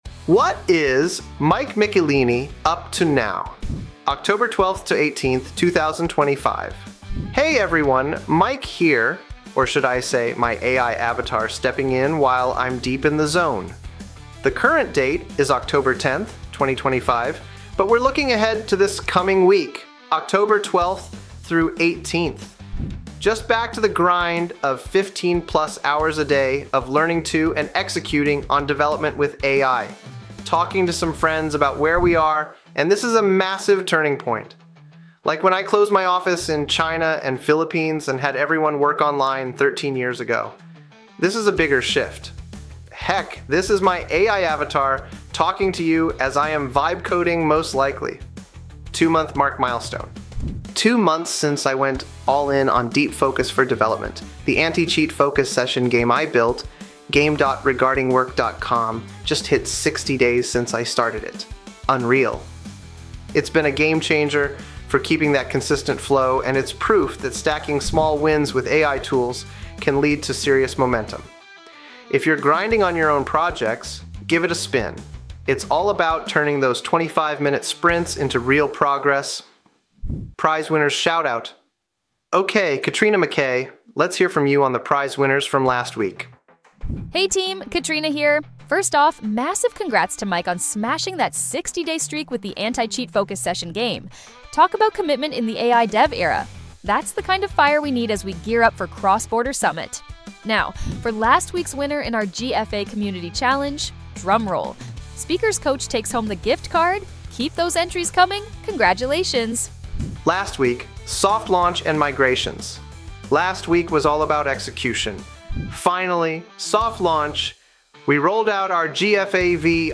Heck, this is my AI avatar talking to you as I am vibe coding most likely.